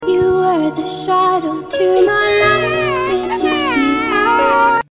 Donald Trump Is Crying Baby Sound Effects Free Download